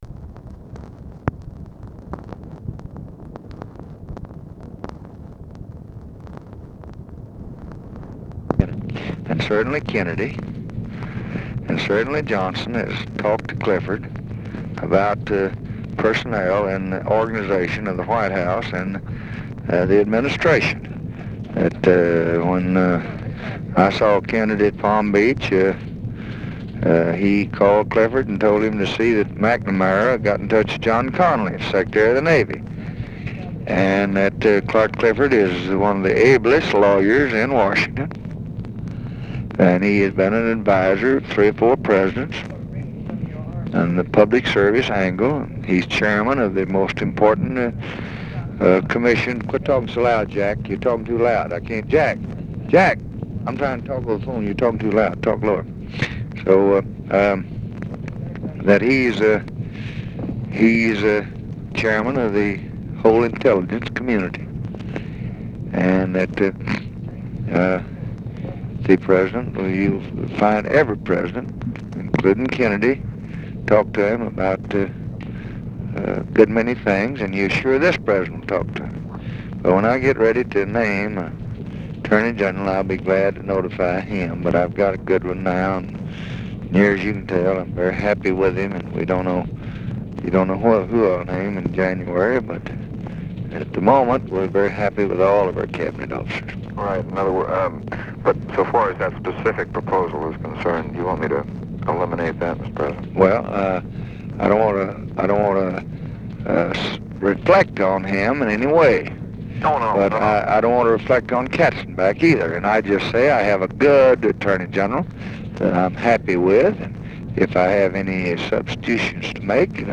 Conversation with MAC KILDUFF and OFFICE CONVERSATION, November 12, 1964
Secret White House Tapes